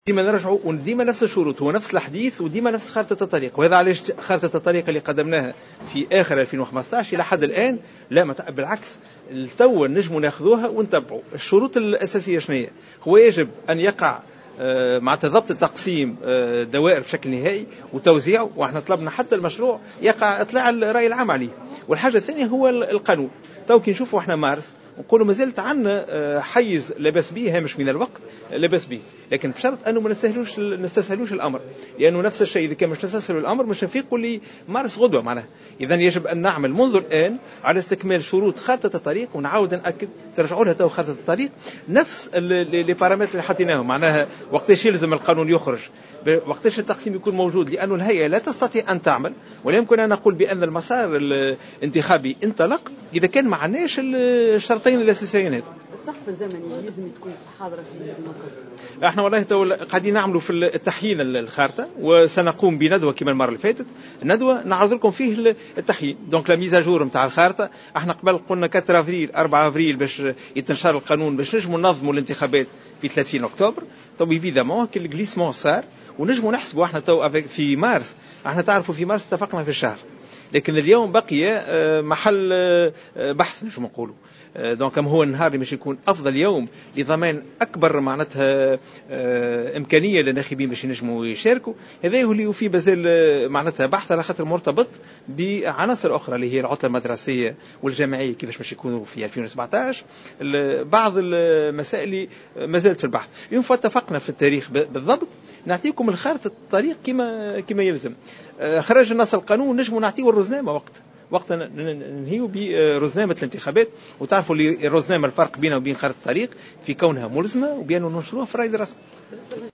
وقال في تصريحات صحفية على هامش ندوة عقدتها هيئة الانتخابات اليوم الخميس في تونس العاصمة، إن بلوغ هذا التاريخ يقتضي ضبط الدوائر الانتخابية وتوزيعها بشكل نهائي واصدار القانون المتعلق بالانتخابات البلدية.